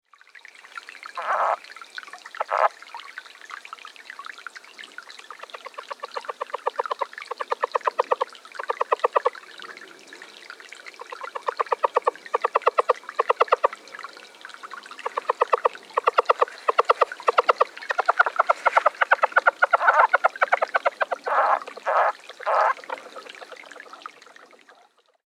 Lowland Leopard Frog - Lithobates yavapaiensis
The call of the Lowland Leopard Frog consists of several short quiet chuckles, sounding like quick, short, kisses.
The following recordings were made at dusk and at night at a small pool in a canyon bottom in Yavapai County, Arizona (shown below at dusk.) Water was slowly flowing into the pool. 3 or 4 frogs were calling.
Sound This is a 25 second continuous recording of two frogs calling at night.